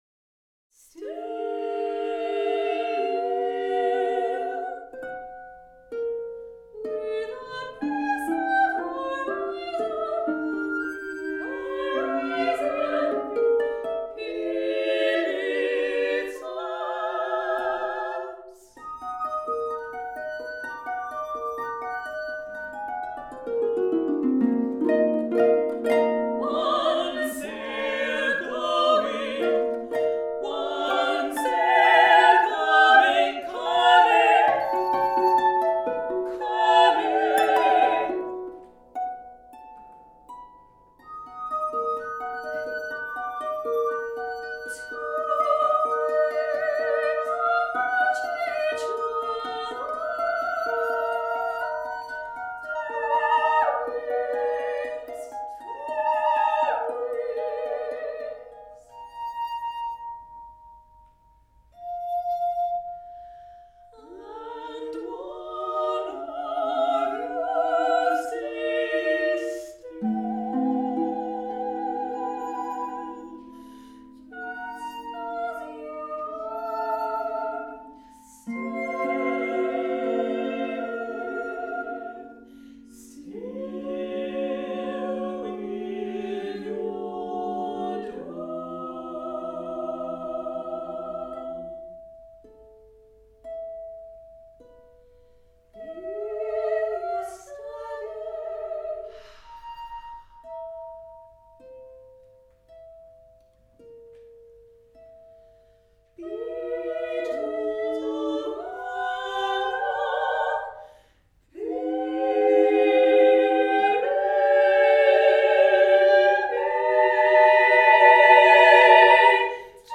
A setting of W.S. Merwin's poem, scored for two sopranos, two contraltos, harp and glass harp